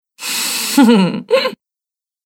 Смешные звуки смеха — люди
Женщина издает звуки сначала только носом, потом смех вырывается наружу